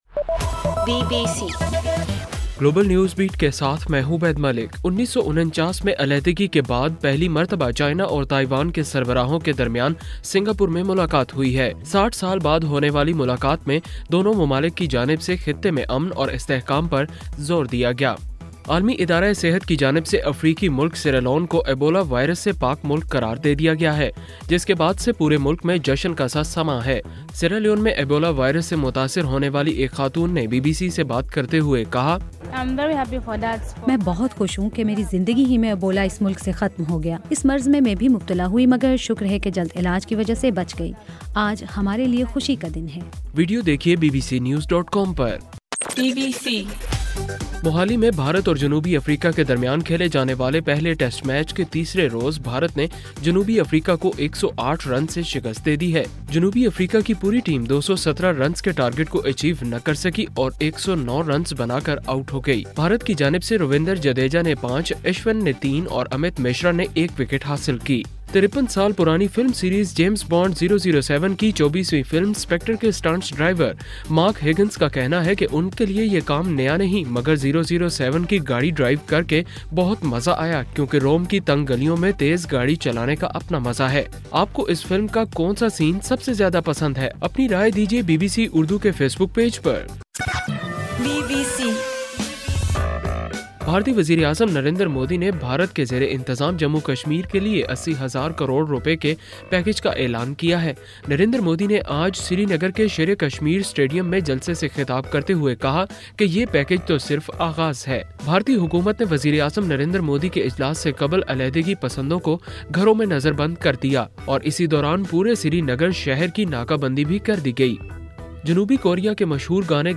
نومبر 7: رات 8 بجے کا گلوبل نیوز بیٹ بُلیٹن